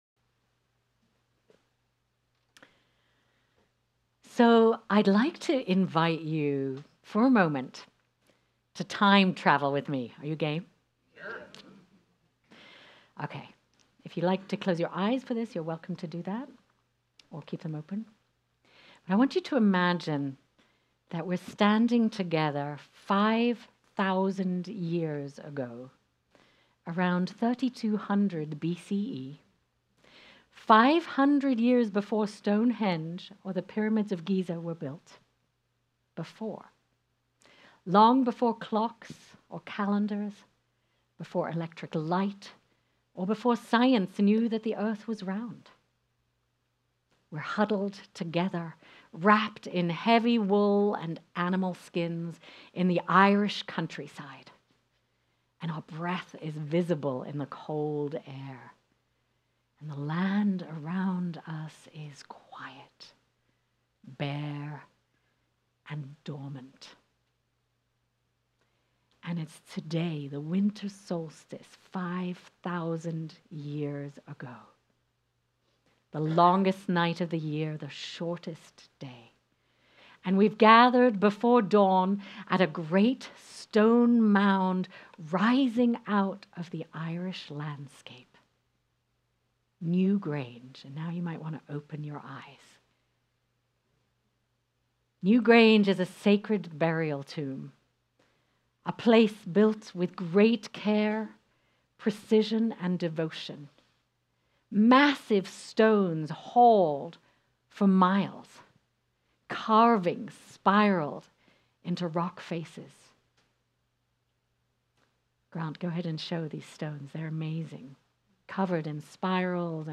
Finding the Light – Solstice Service